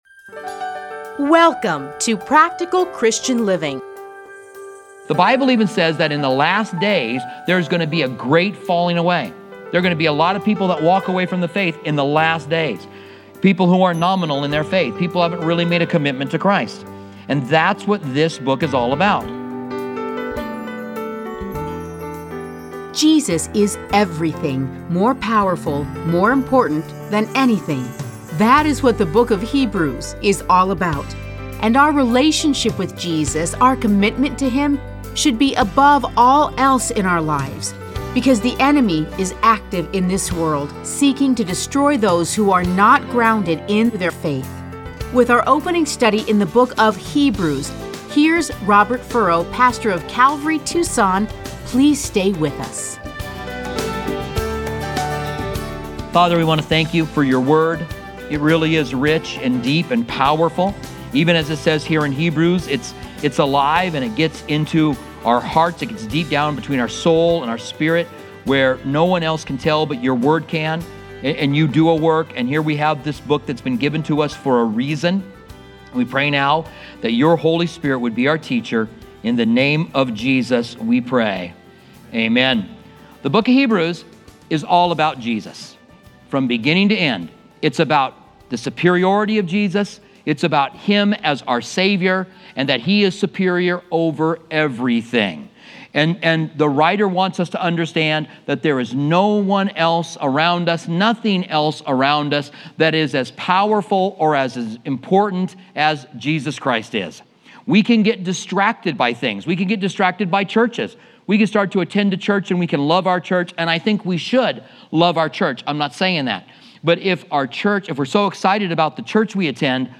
Listen to a teaching from Hebrews 1:1-4.